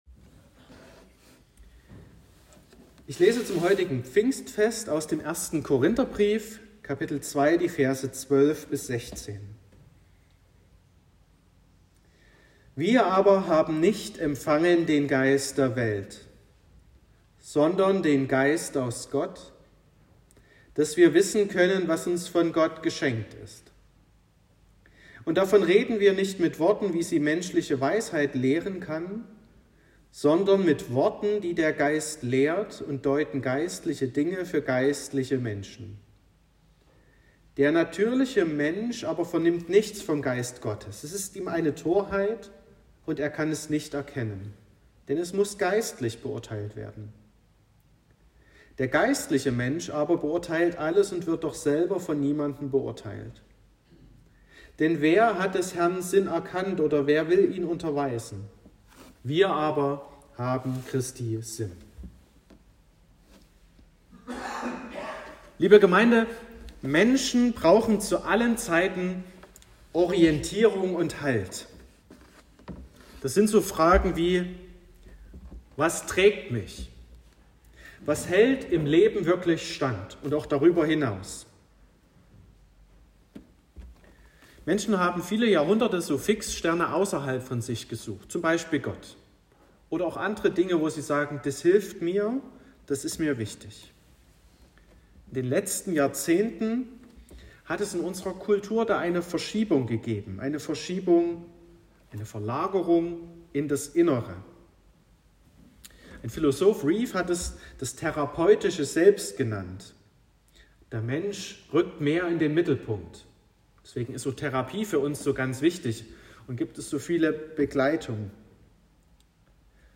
28.05.2023 – Gottesdienst
Predigt und Aufzeichnungen
Predigt (Audio): 2023-05-28_Heiliger_Geist_-_empfangen__unterscheiden__lernen.m4a (9,4 MB)